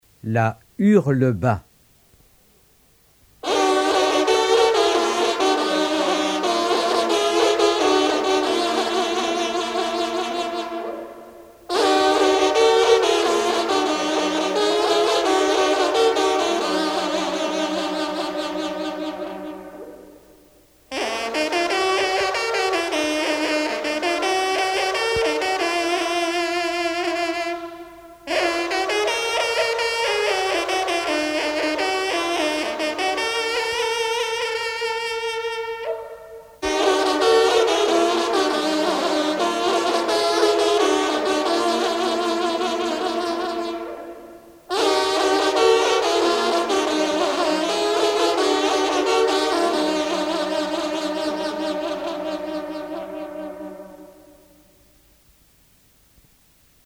trompe - fanfare
circonstance : vénerie